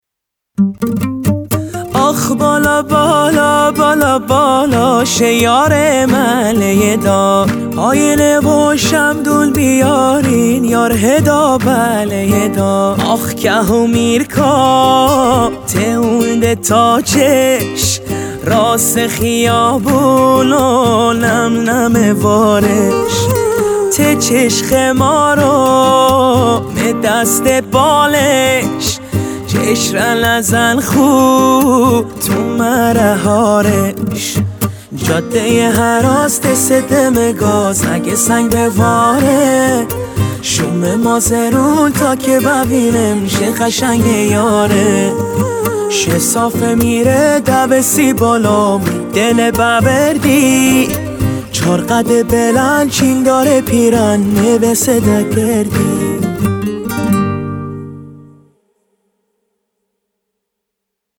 ریتمیک ( تکدست )